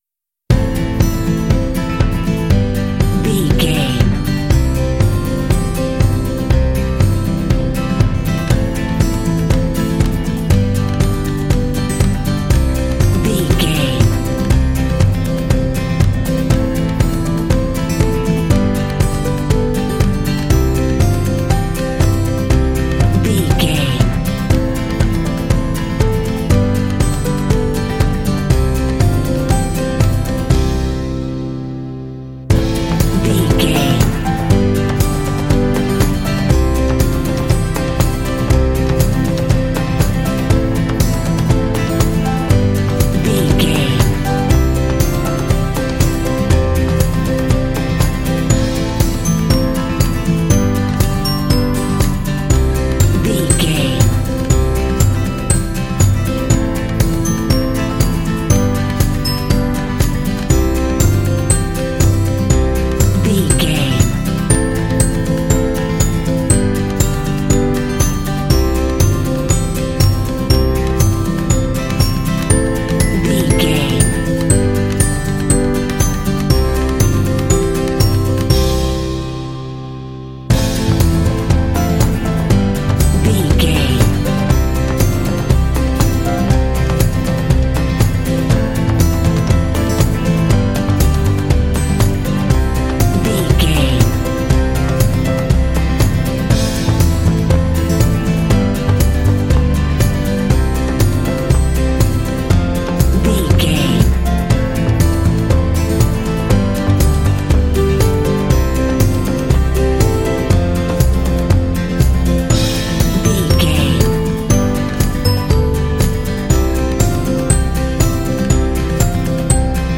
Ionian/Major
inspirational
hopeful
soothing
acoustic guitar
bass guitar
piano
strings
percussion